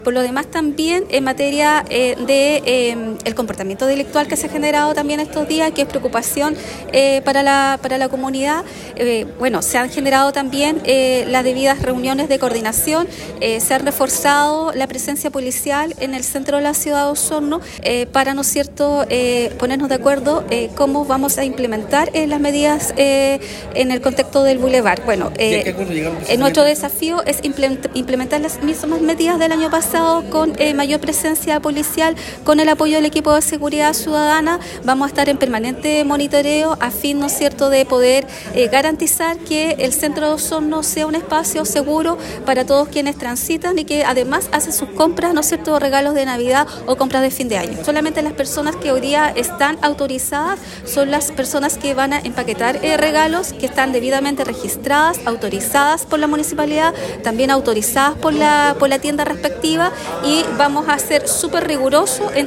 Uno de los puntos clave mencionados por Pailalef fue el plan de seguridad implementado en el centro de Osorno. En esta área, se ha habilitado un programa específico para evitar el comercio ilegal, especialmente de productos que no cumplen con los estándares de seguridad o que pueden ser peligrosos para la salud de los consumidores.